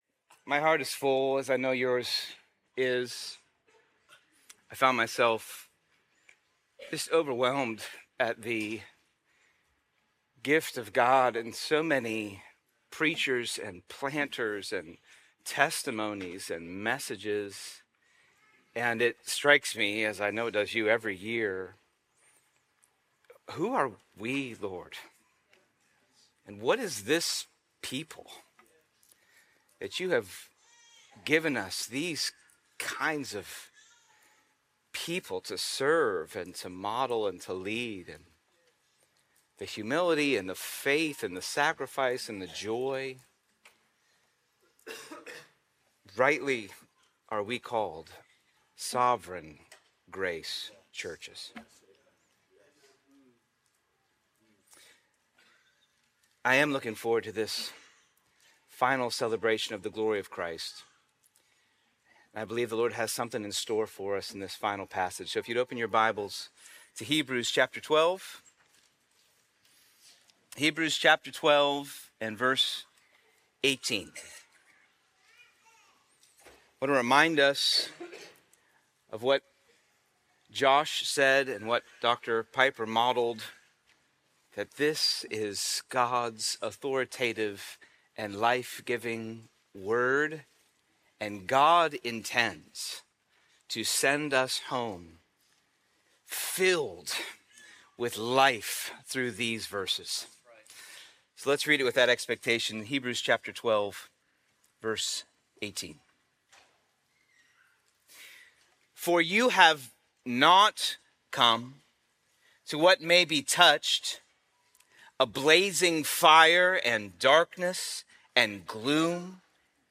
2025 Pastors Conference: Christ Our Glory